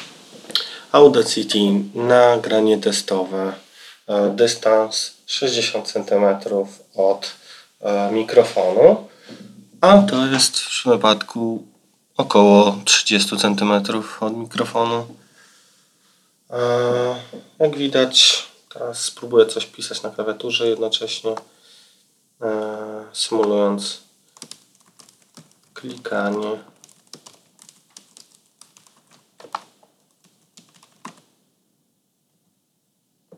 Oraz link do testu mikrofonów wbudowanych:
mikrofony_e6430u.wav